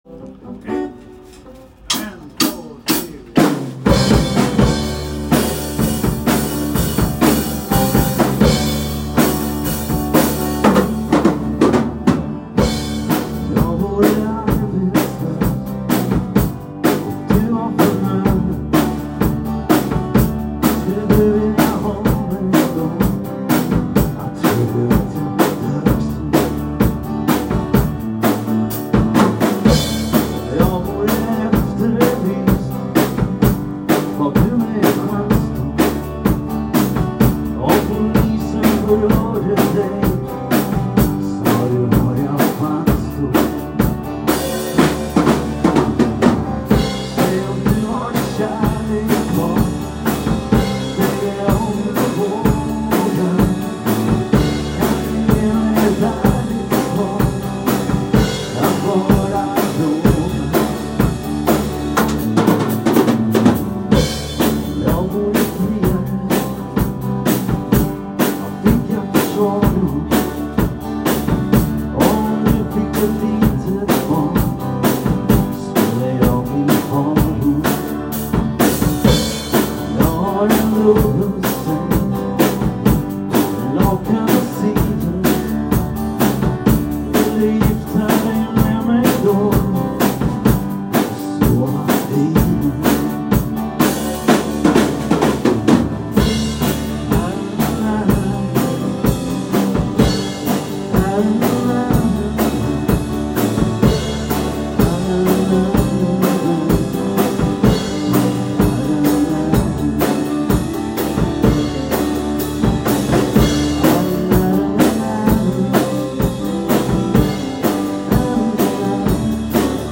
En hoe klonk die repetitie nou?